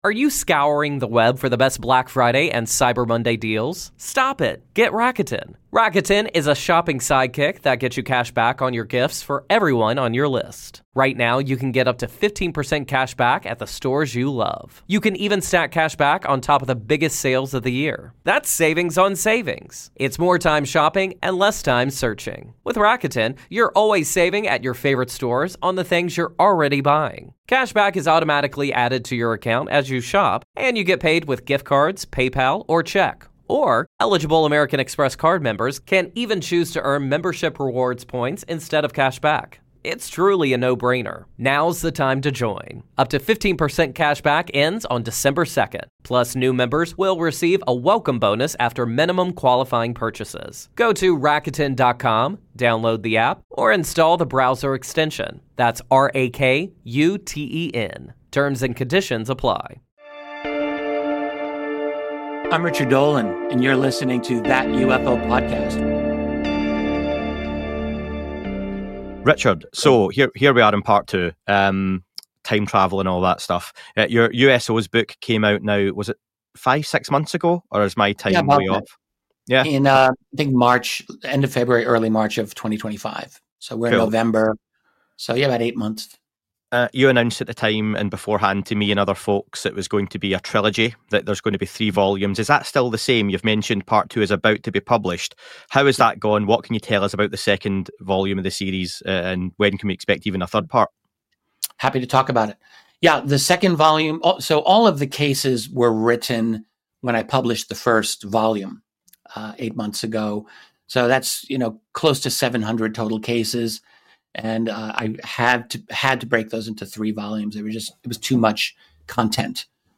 Listener questions cover everything from historical UFO sightings in China to the complexities of abduction experiences. A comprehensive and engaging discussion for anyone fascinated by the UFO phenomenon and its wider implications.